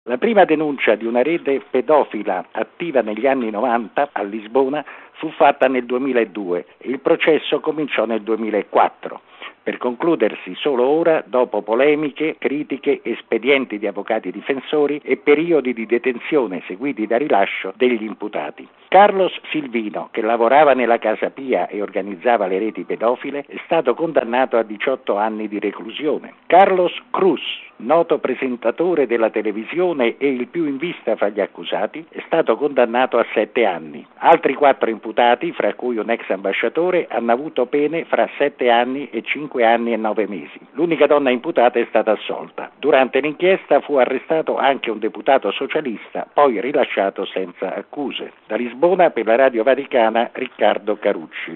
I giudici di Lisbona hanno preso in esame la vicenda della Casa Pia, un’istituzione che dal 1780 aiuta minori in difficoltà. Il servizio da Lisbona: